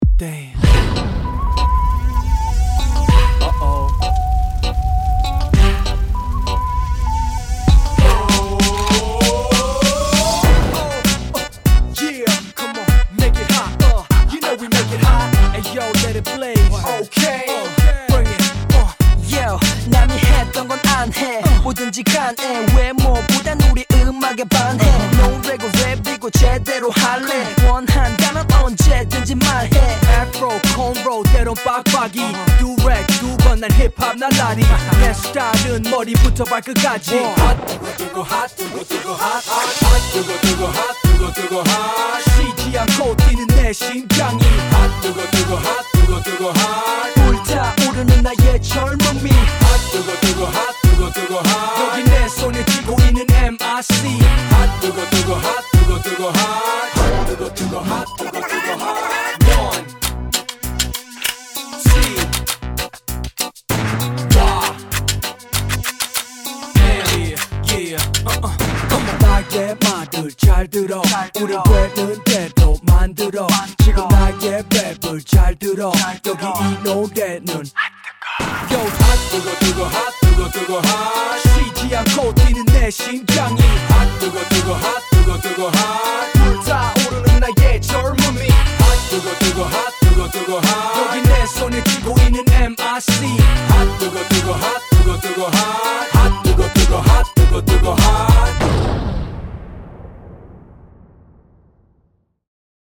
BPM98
Audio QualityPerfect (High Quality)
K-Pop